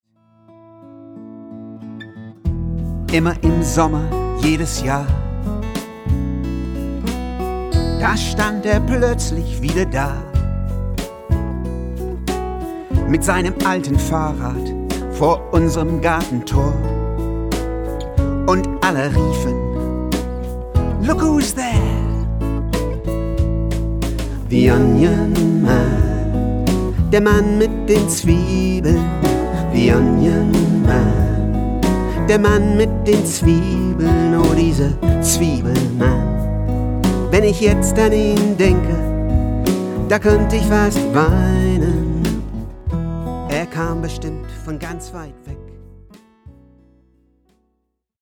Dieses erste Studio-Album
Klavier, Perkussion
Kontrabass, Gitarre